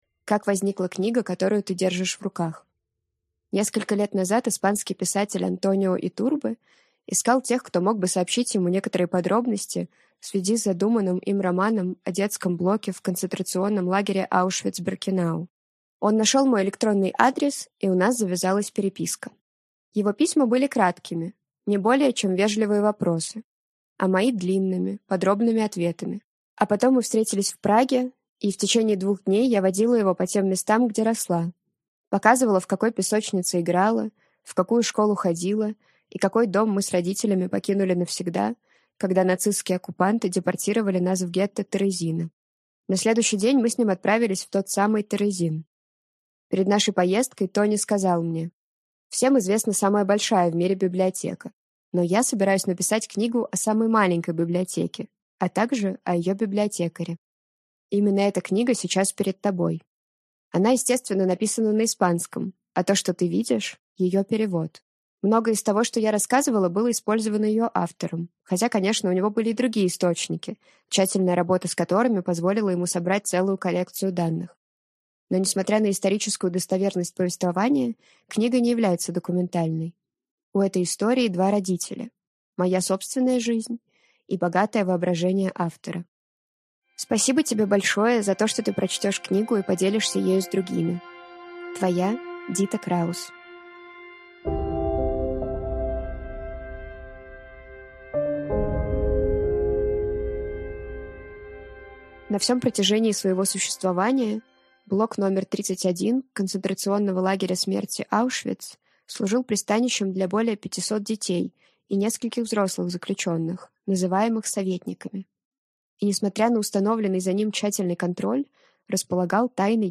Аудиокнига Хранительница книг из Аушвица | Библиотека аудиокниг